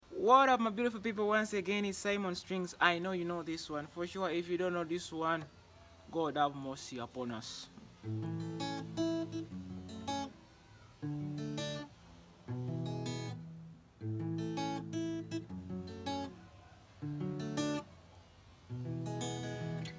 guitar cover